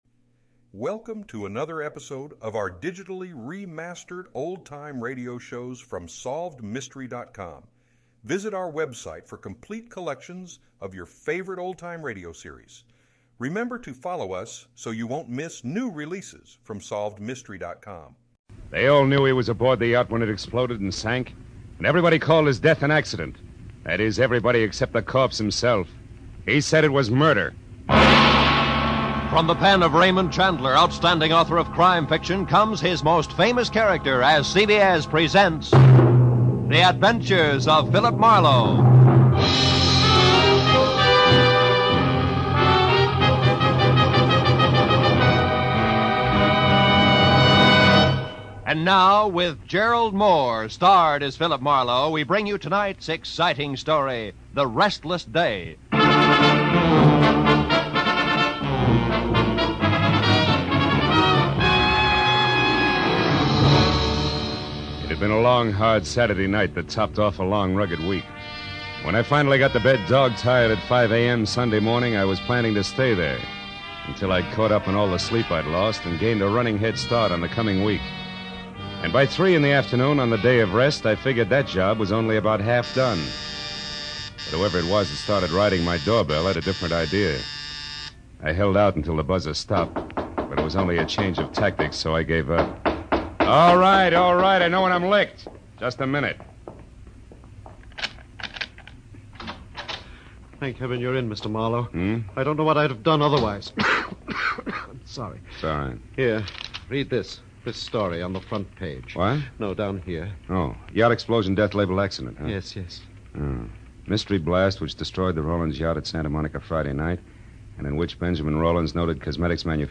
Gerald Mohr starred as Marlowe, and the show was known for its gritty and realistic portrayal of crime and the criminal underworld.
In addition to Mohr's excellent portrayal of Marlowe, the show also featured a talented supporting cast of actors and actresses, including Howard McNear and Parley Baer.